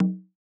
AIR Bongo.wav